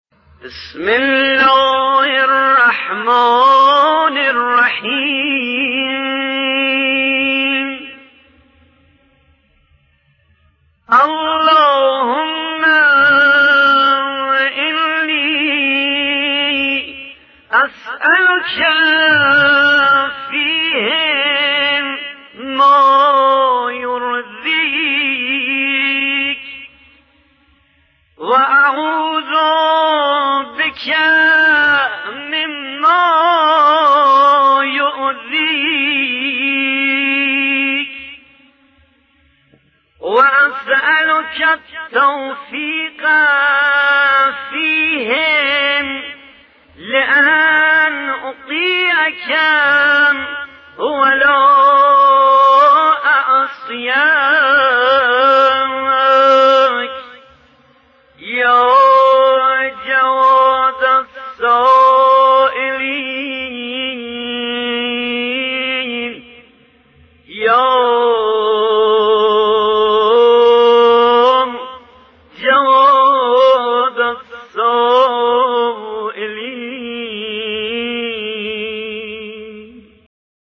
قرائت جزء بیست و چهارم ، مناجات و دعای روز بیست و چهارم ماه مبارک رمضان - تسنیم